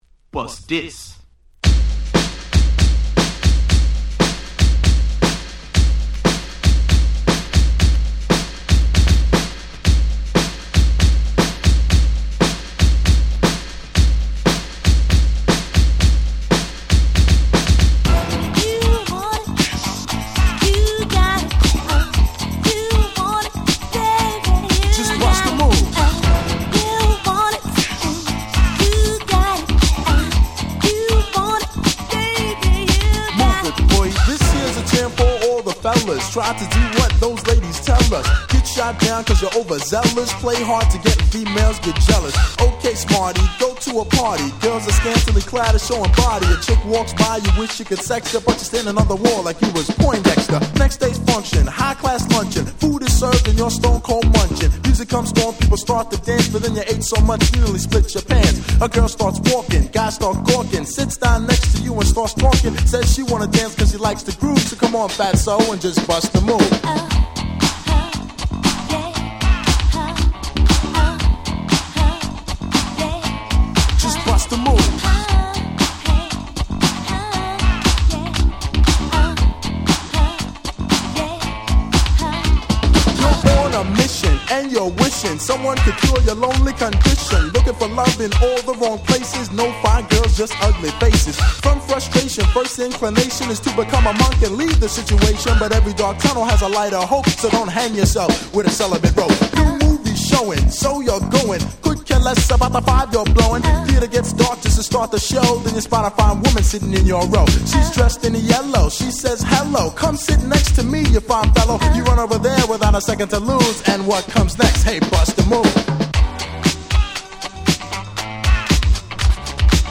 89' Nice Remix !!